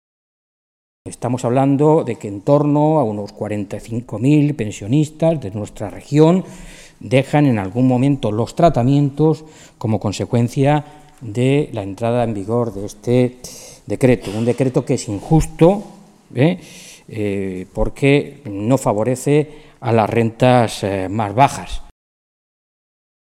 Mora se pronunciaba de esta manera esta mañana, en Toledo, en una comparecencia ante los medios de comunicación en la que insistía en que ese decreto, que fue el recorte de los decretos en Sanidad, se había revelado profundamente injusto «fundamentalmente con aquellos pensionistas que cobran pensiones más bajas, las que no llegan a los 810 euros, que es la pensión media en Castilla-La Mancha».
Cortes de audio de la rueda de prensa